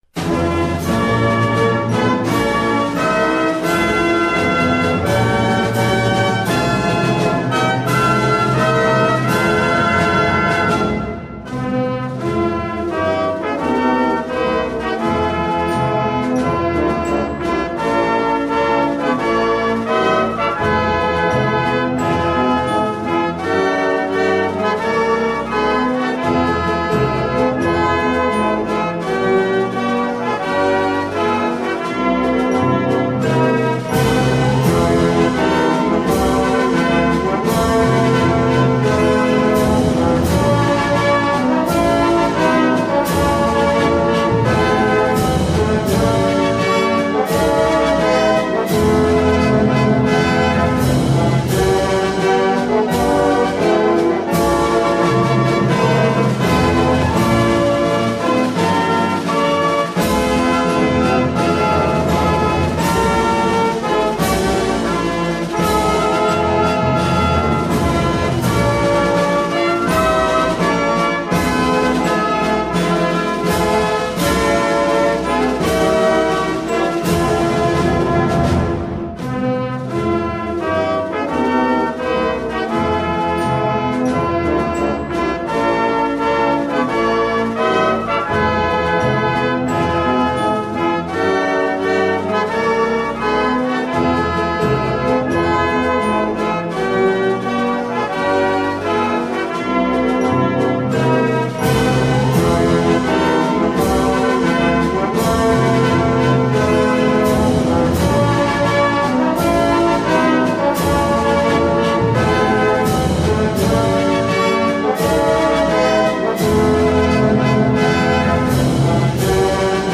величественное музыкальное произведение
в инструментальном исполнении